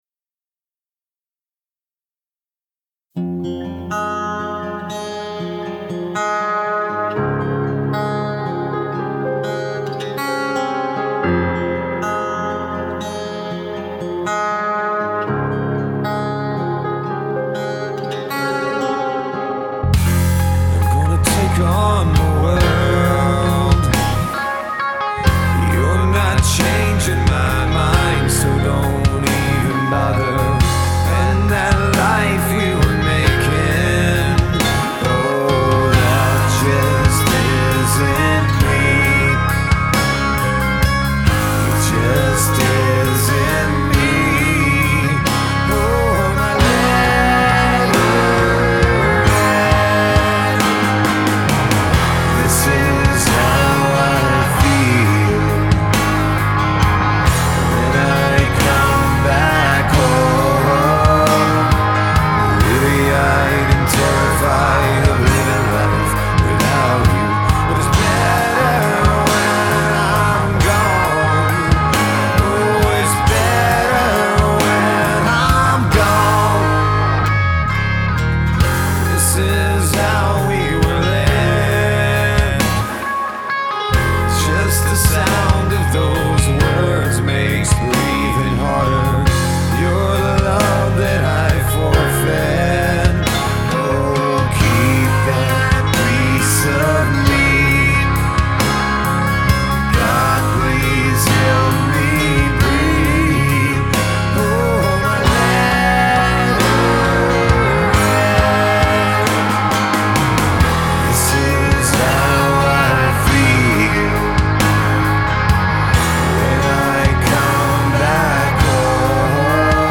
I love the sound from the guitars in the intro but I would love to find a way to make it cleaner.